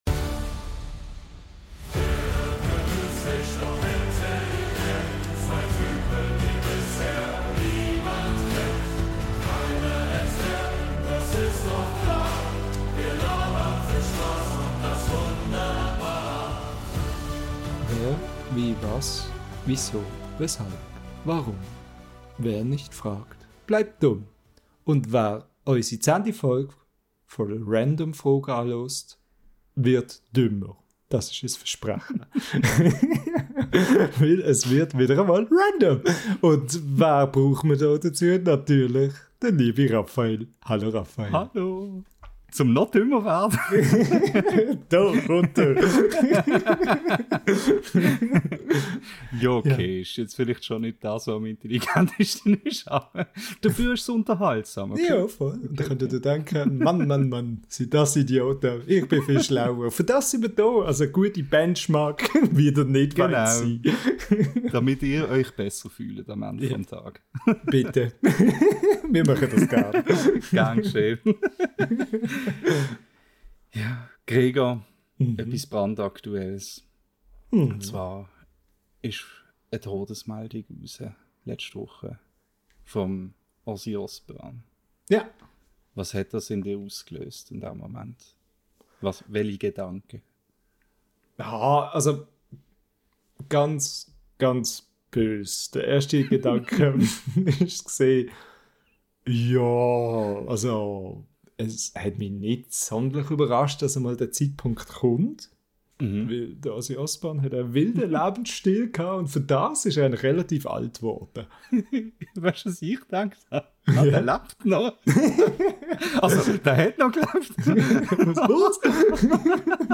Das und noch viel mehr hört ihr in der neuen Folge unseres schweizerdeutschen Podcasts.